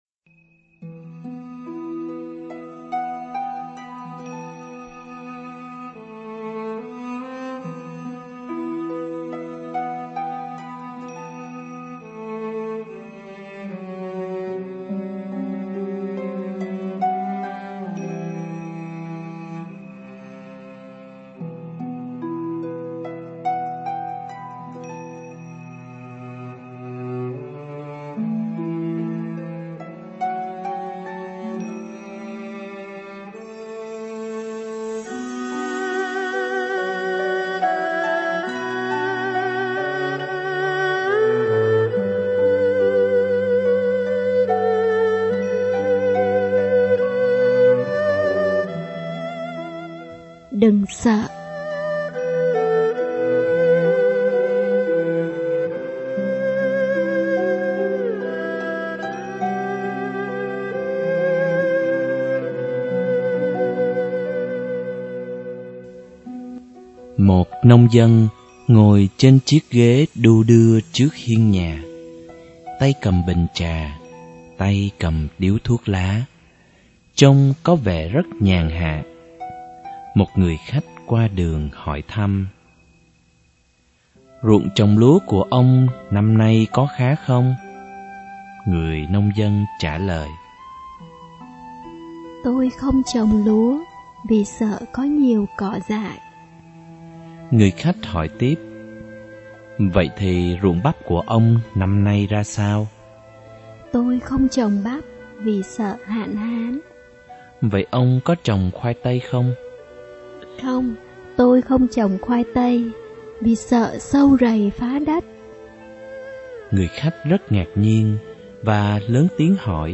* Thể loại: Suy niệm